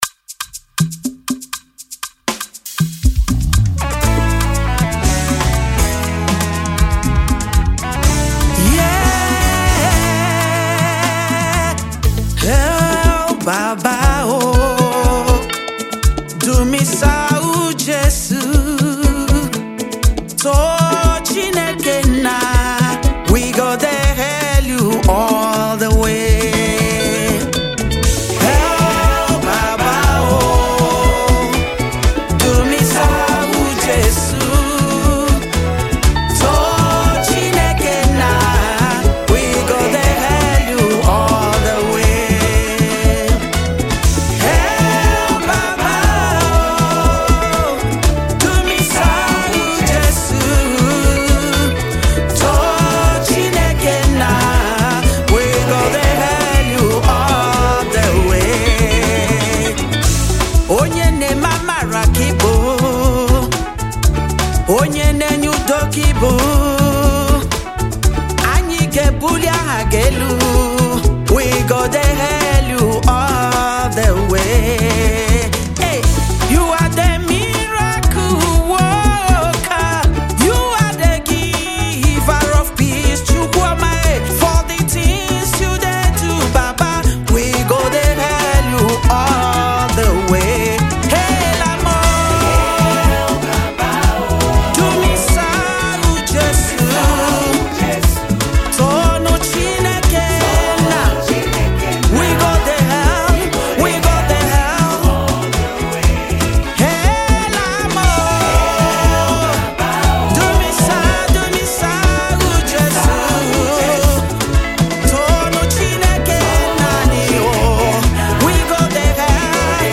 Versatile Nigerian gospel music minister
praise anthem